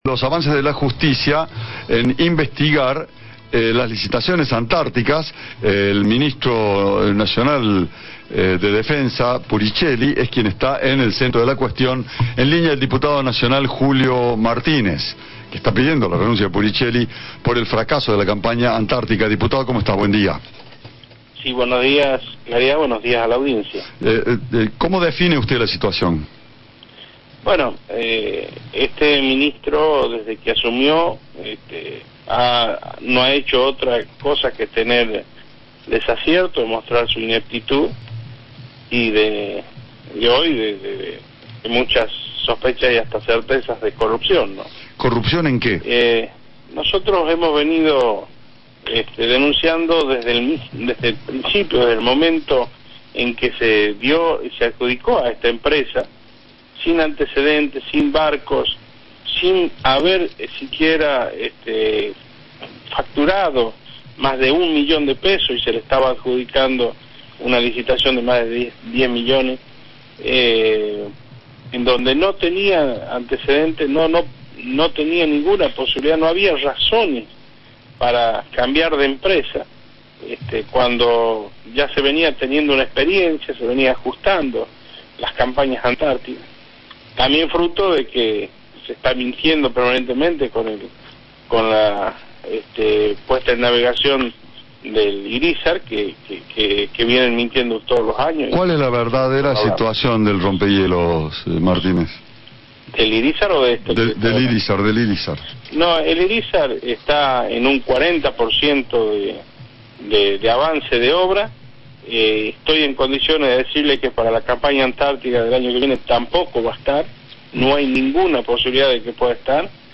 En diálogo con Cadena 3 el diputado nacional por La Rioja, Julio Martínez (UCR) consideró que desde que Puricelli asumió «demostró su ineptitud y hoy hay muchas sospechas y certezas de corrupción».